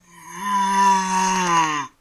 sounds_undead_moan_03.ogg